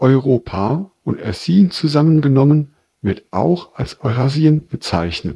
Added first samples for TensorFlowTTS
sample03-TensorFlowTTS.wav